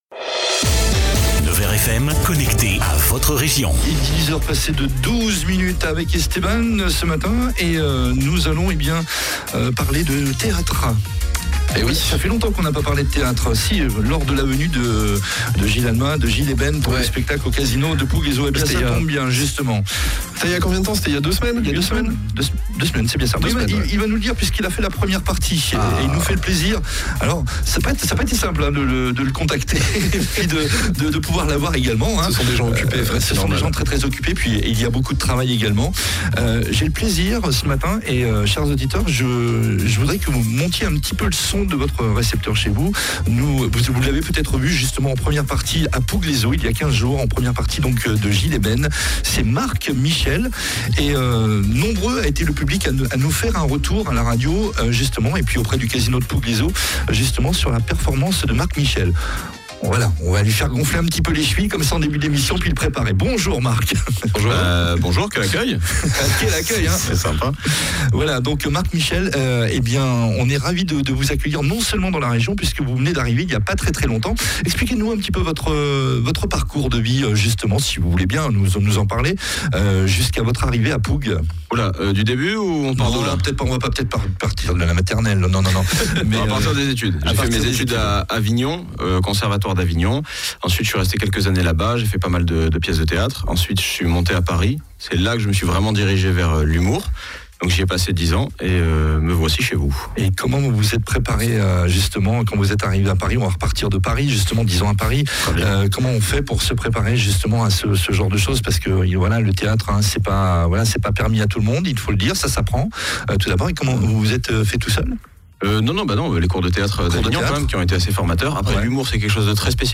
est venu dans la matinale pour présenter son one-man show